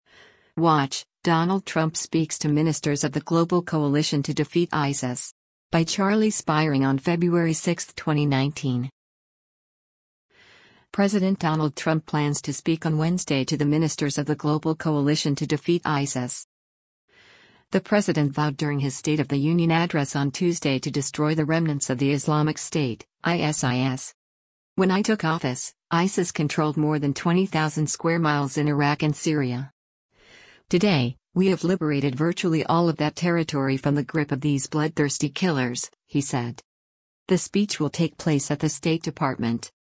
WATCH: Donald Trump Speaks to Ministers of the Global Coalition to Defeat ISIS
The speech will take place at the State Department.